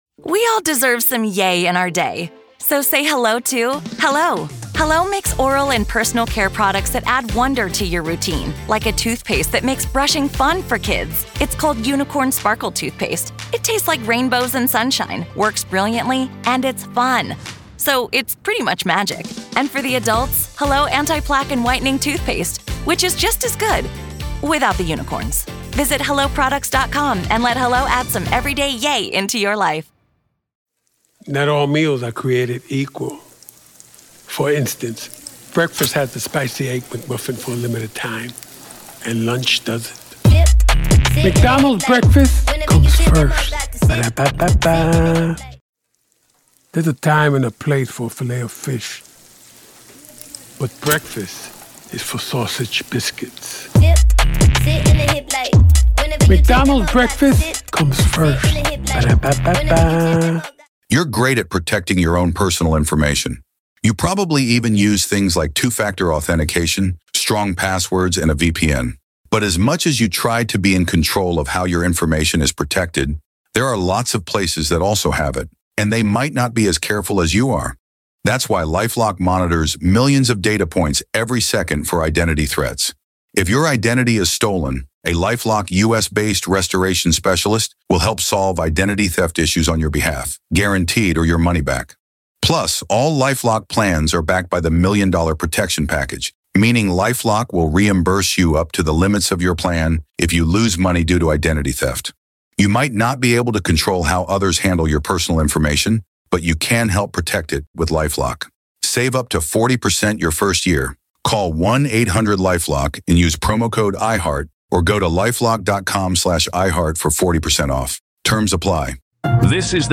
opening statements
defense attorney